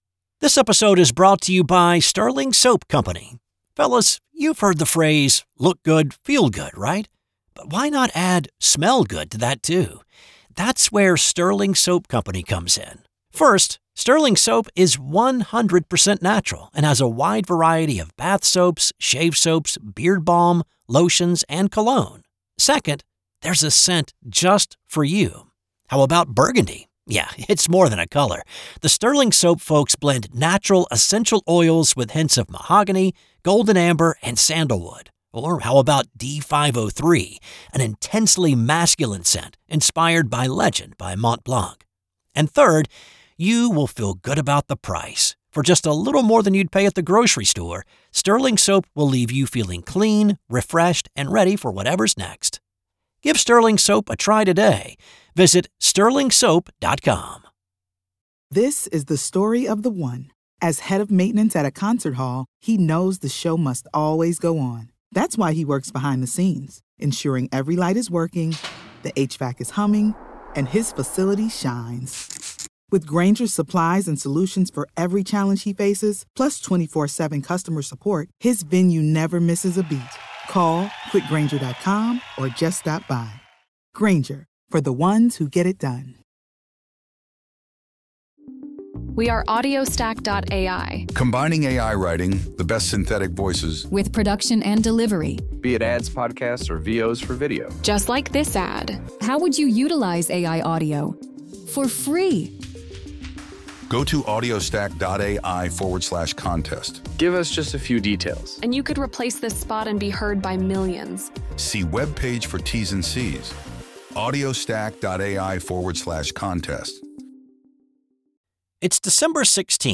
'This Day in Sports History' is a one person operation. I research, write, voice, and produce each show.